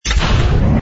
engine_pi_freighter_start.wav